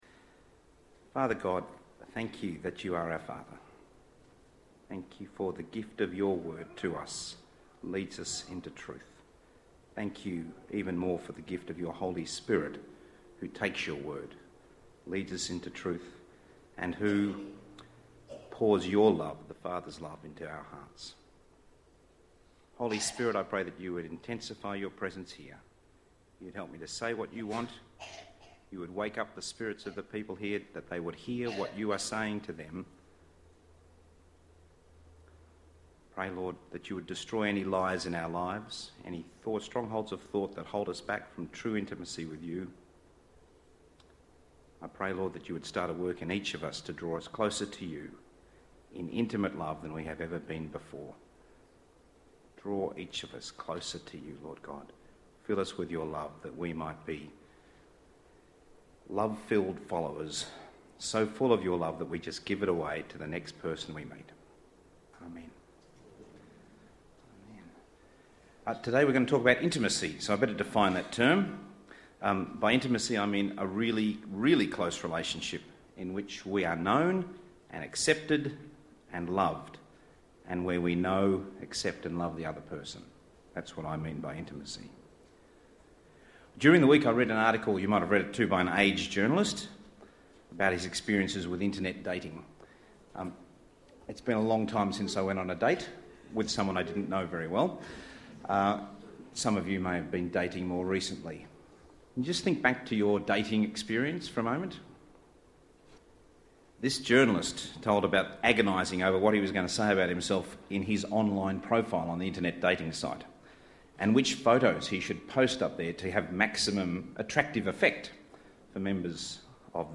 Sermons | St Alfred's Anglican Church
Guest Speaker